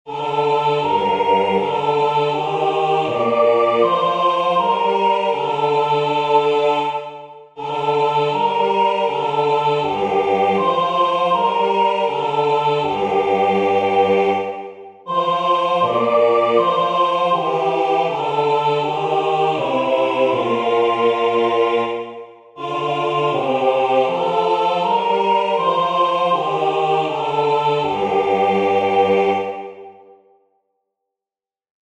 Skaņdarbs lieliski piemērots senās mūzikas praktizēšanai, visas balsis dzied vienā ritmā.